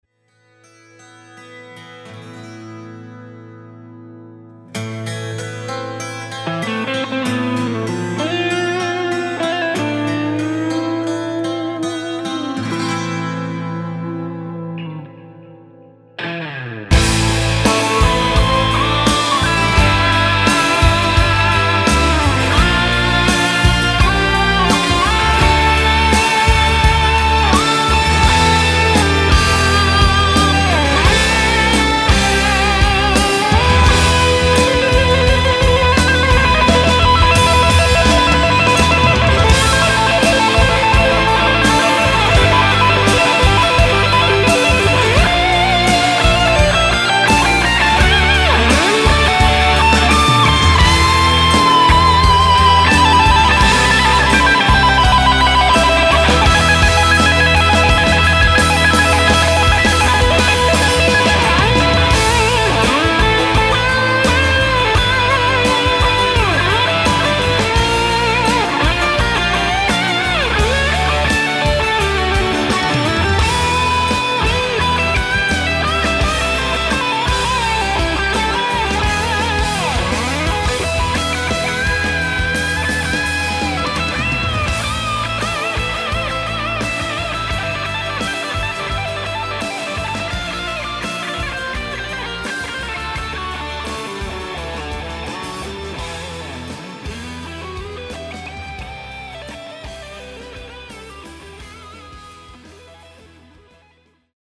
guitars, percussion
drums
bass
keyboards
violin